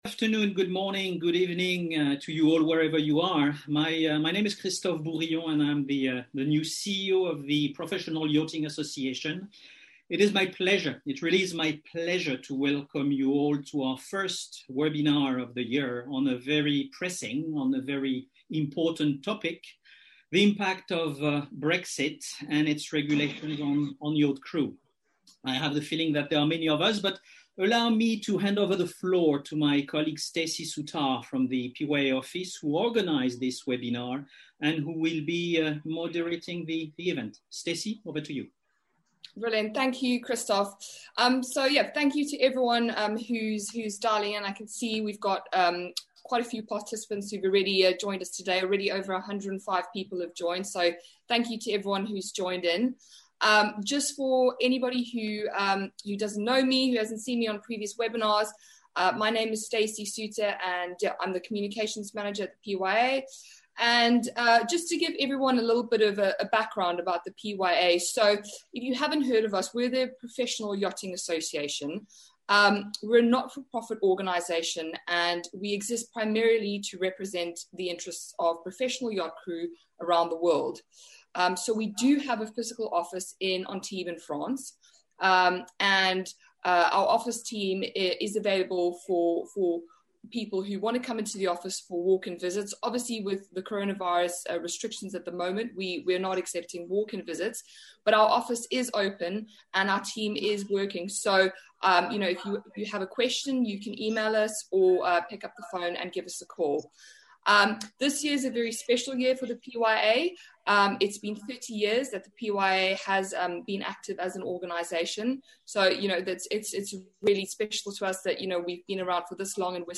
PYA WEBINAR REPLAY